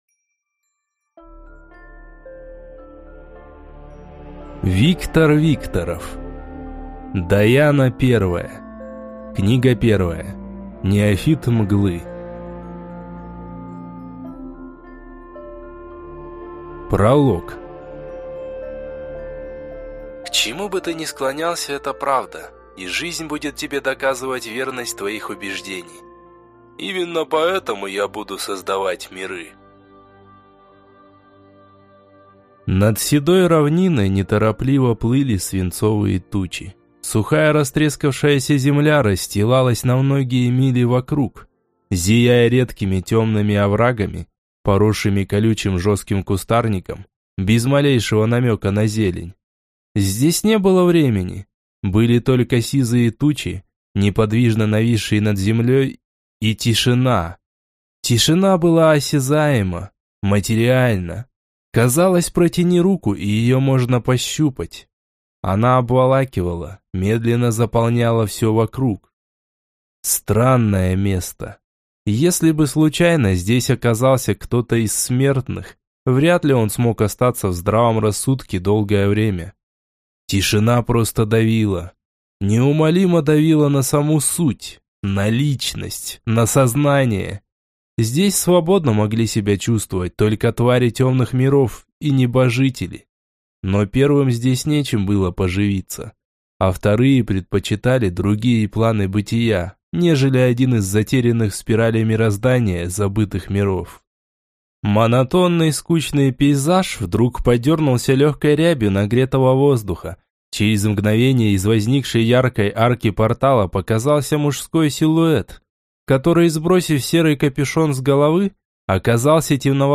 Аудиокнига Даяна I. Неофит Мглы | Библиотека аудиокниг